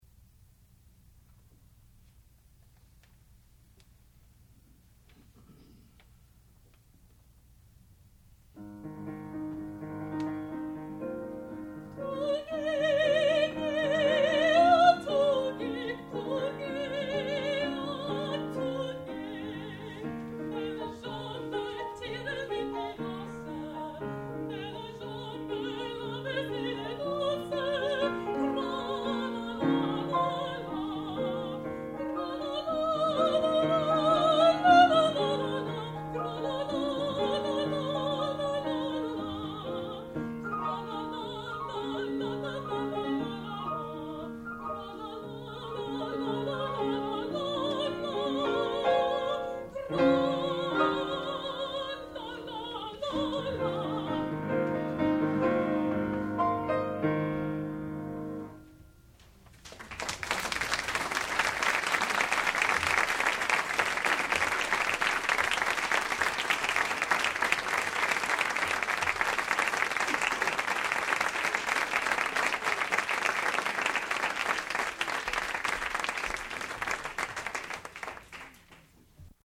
sound recording-musical
classical music
piano
Master's Recital
mezzo-soprano